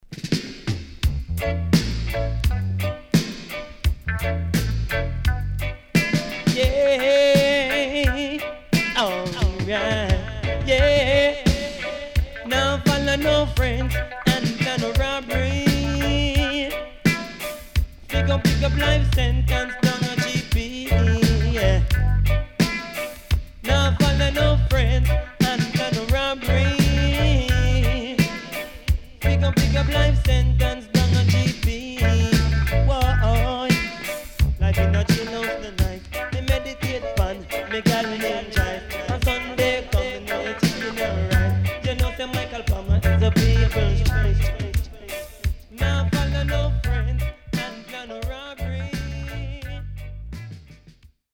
HOME > DISCO45 [DANCEHALL]
SIDE A:少しチリノイズ入りますが良好です。